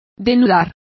Complete with pronunciation of the translation of denude.